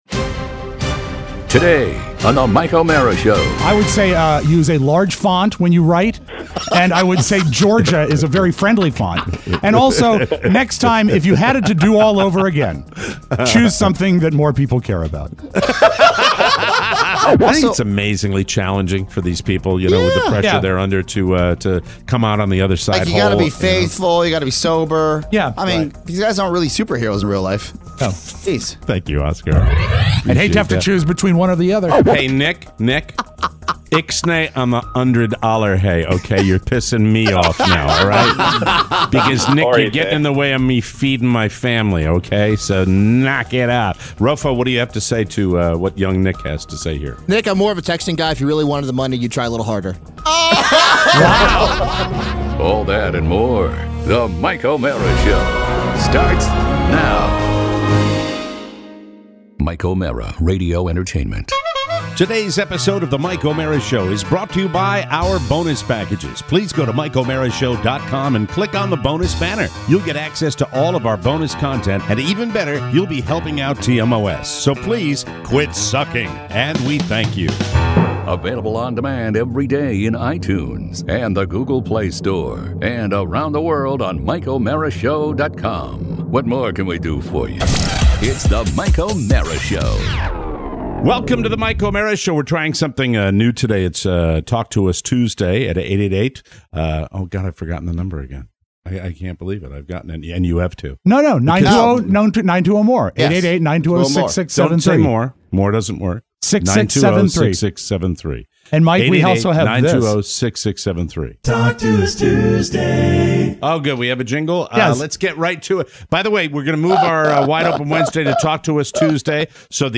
Today, marks marks the return of phone calls with “Talk to Us Tuesday”! your calls